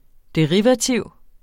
derivativ 1 substantiv, intetkøn Bøjning -et, -er, -erne Udtale [ deˈʁivaˌtiwˀ ] Betydninger 1.